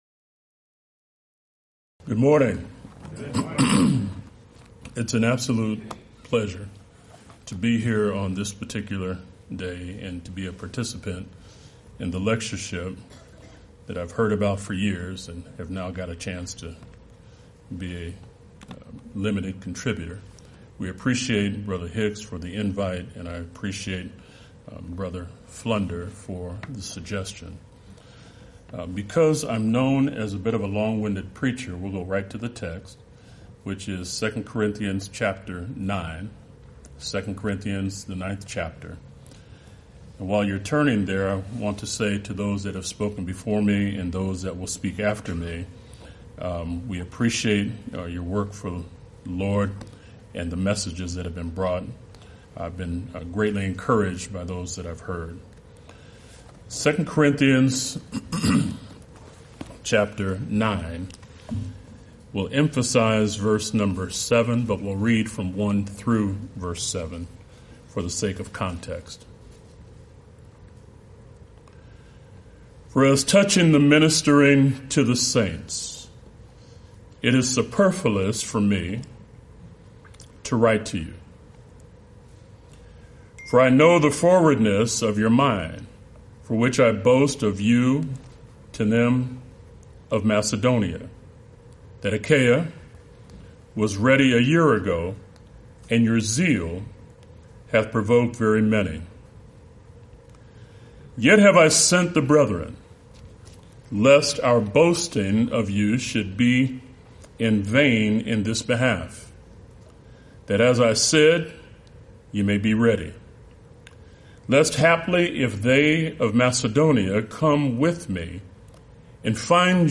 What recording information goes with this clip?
Series: Lubbock Lectures Event: 26th Annual Lubbock Lectures